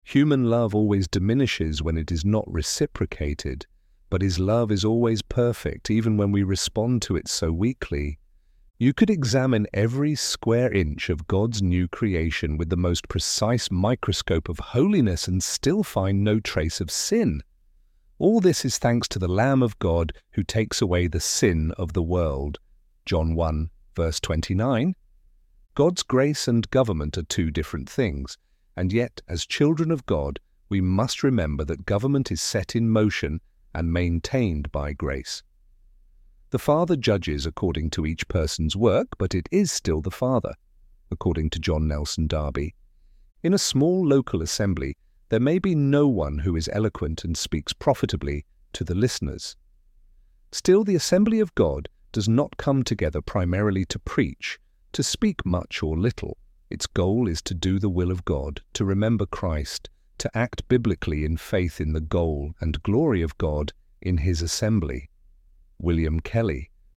ElevenLabs_Lines__Lessons_3.mp3